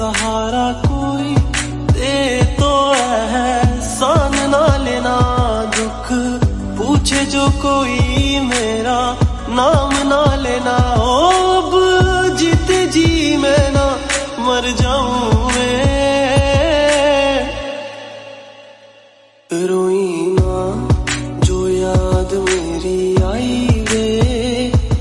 Sad Ringtones